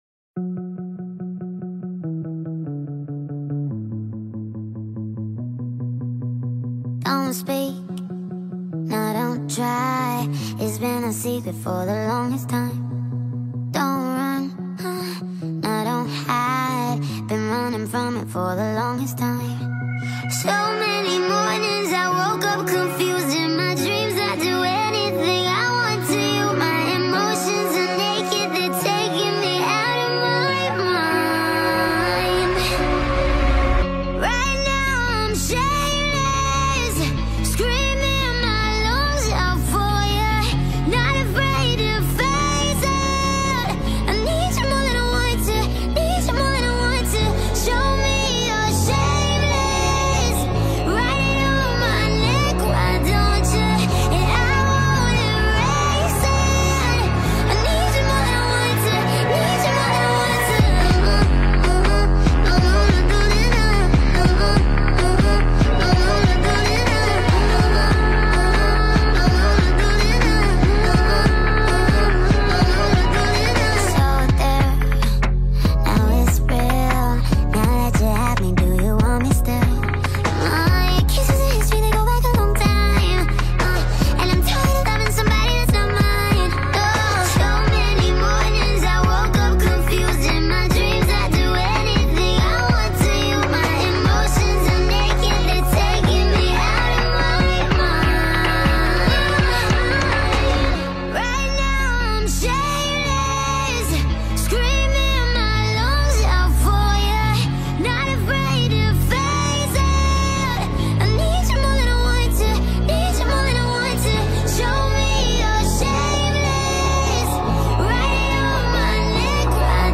TikTok remix